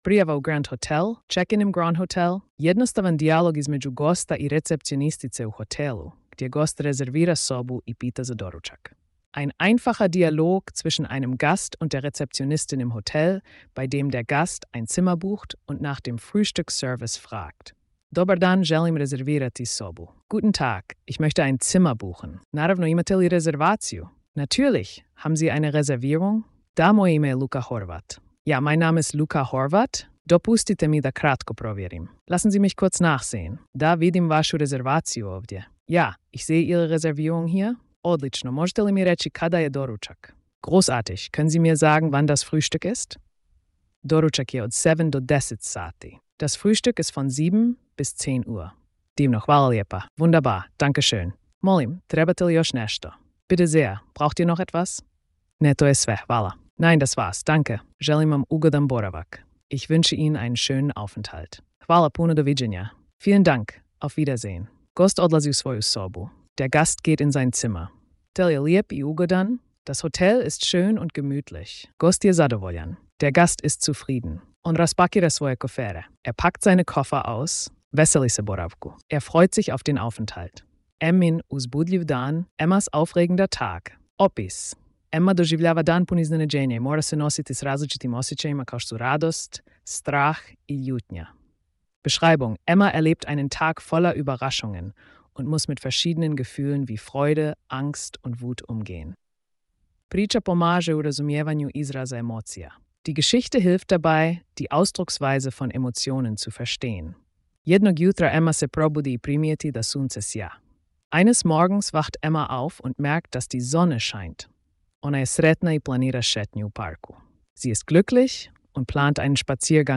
Erlebe interaktive Dialoge im Hotel und entdecke kroatische Vokabeln für emotionale Ausdrücke und interkulturellen Austausch.